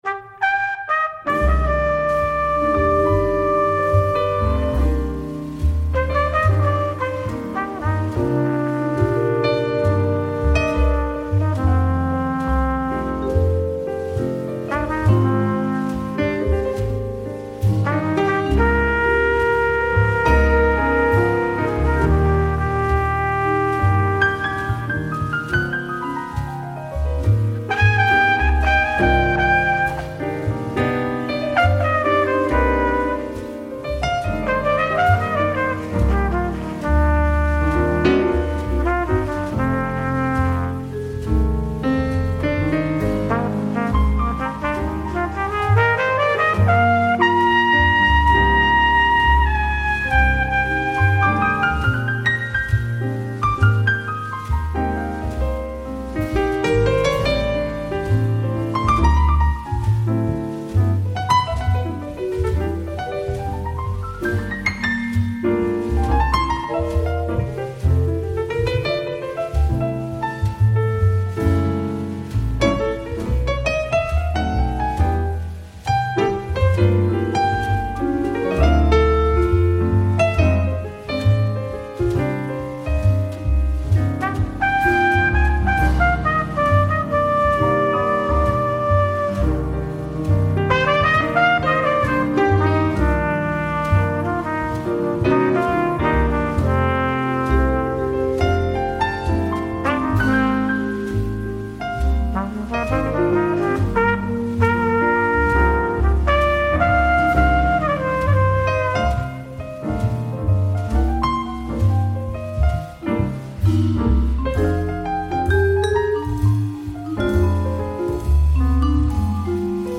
trompette
vibraphone